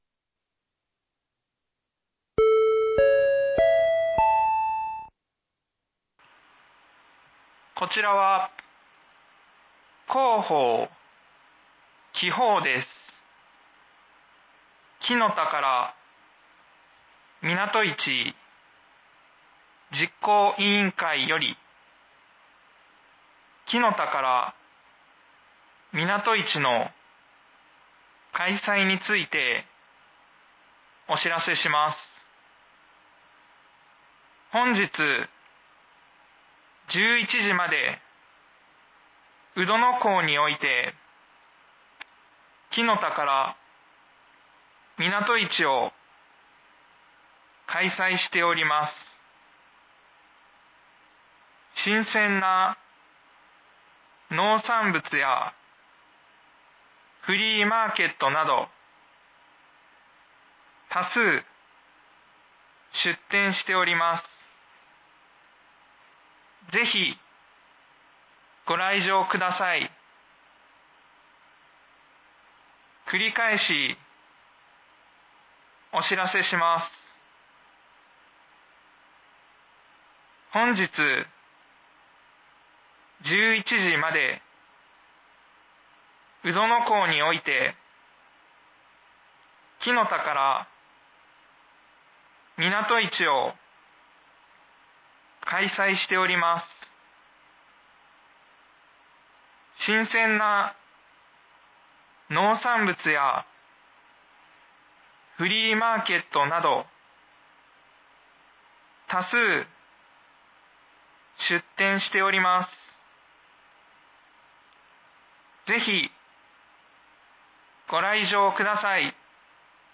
紀宝町防災無線情報
放送音声